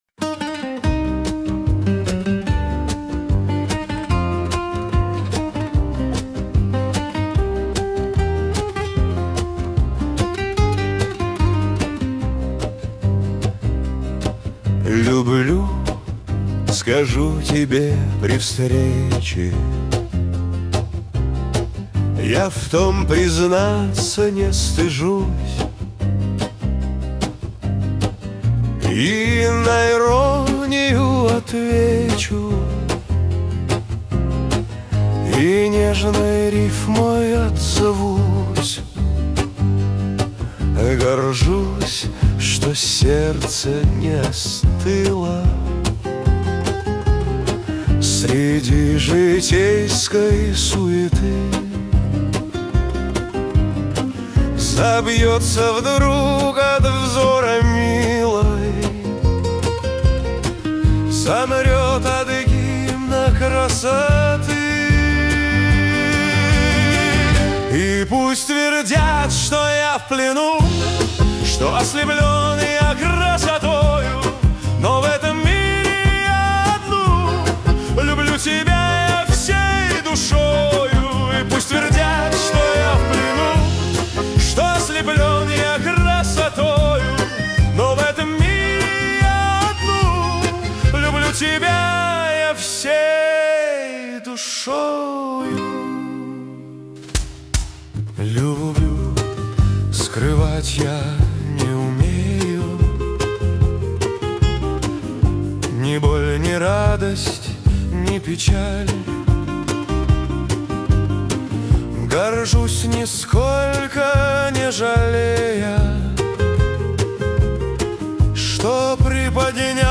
13 декабрь 2025 Русская AI музыка 93 прослушиваний
Трогательная песня о любви